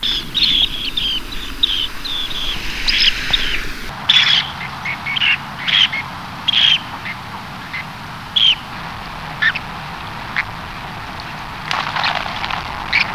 Dunlin
Calidris alpina
VOICE: A deep "kreep" call note.